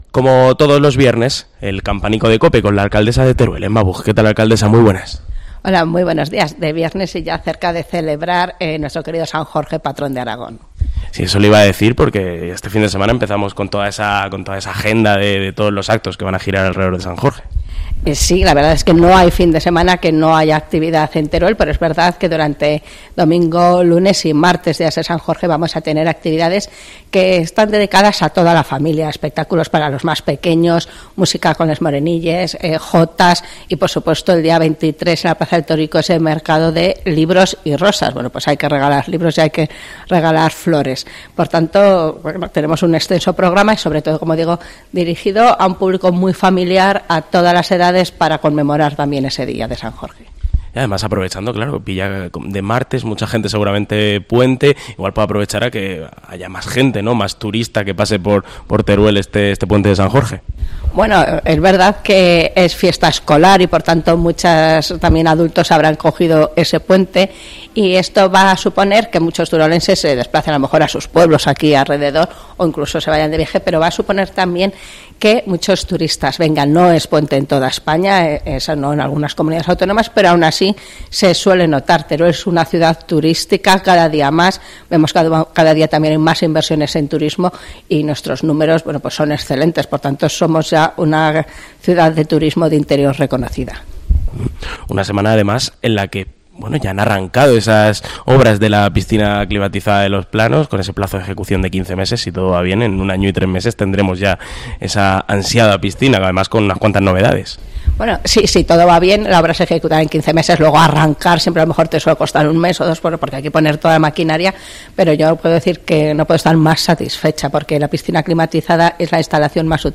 AUDIO: Como todos los viernes, el Campanico de COPE con la alcaldesa de Teruel, Emma Buj